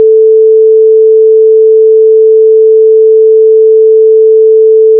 Music on hold
holdmusic.wav